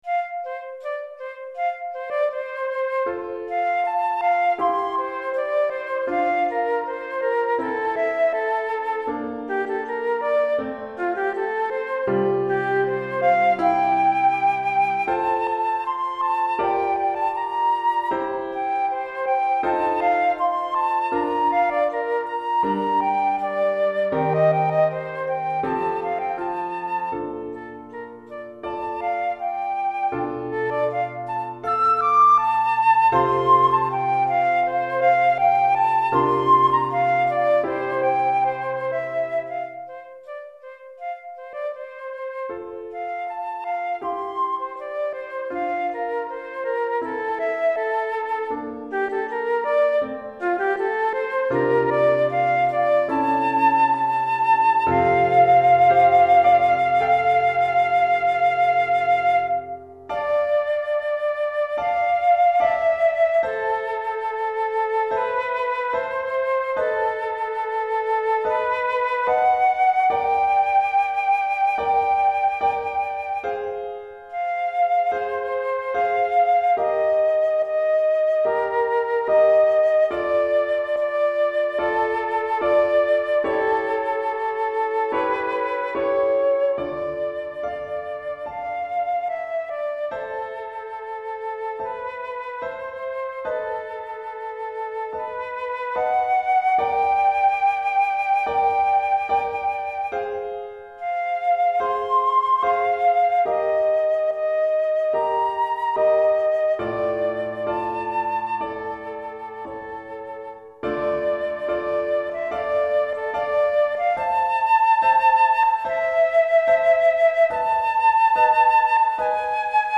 Pour flûte et piano 3 mouvements DEGRE fin de cycle 1 Durée